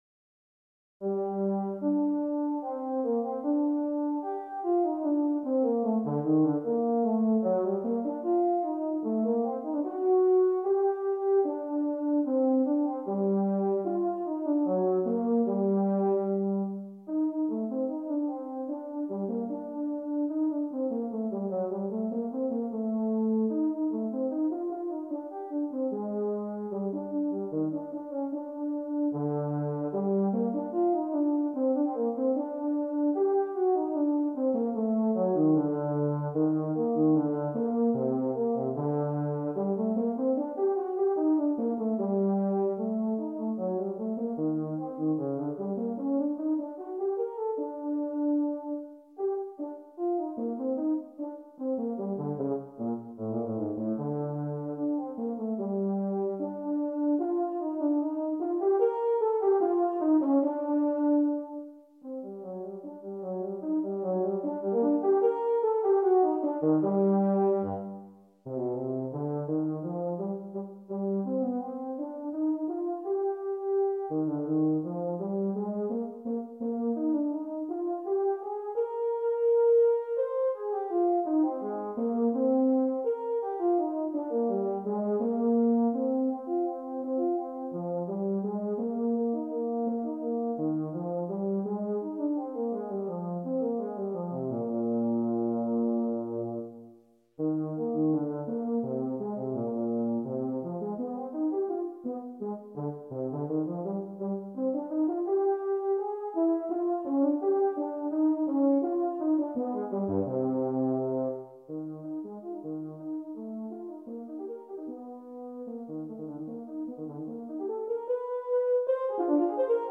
Voicing: Euphonium Methods/Studies/Etudes